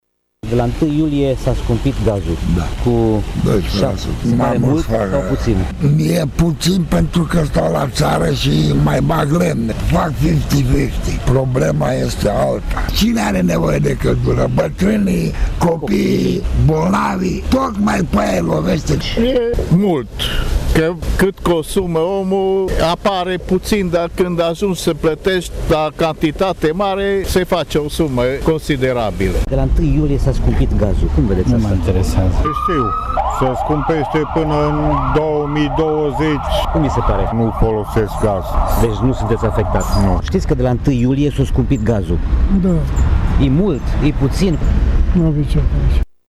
Mai puțini sunt cei revoltați, dar aceștia sunt și cei mai vocali: